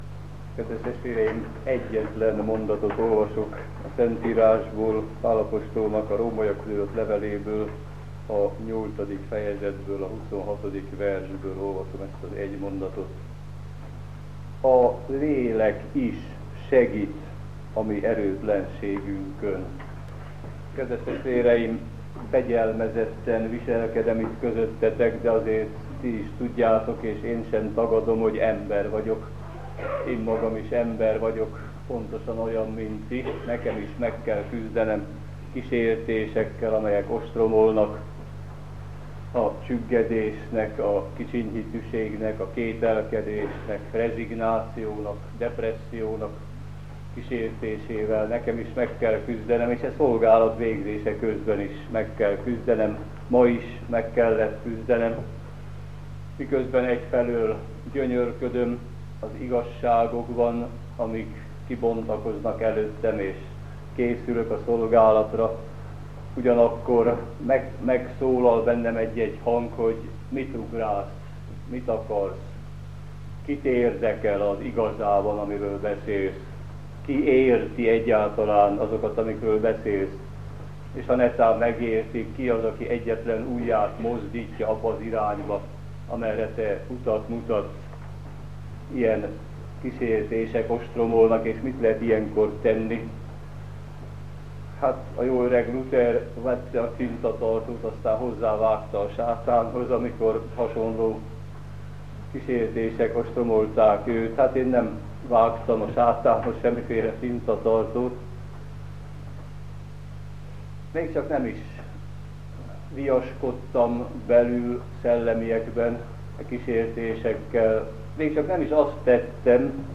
igehirdetései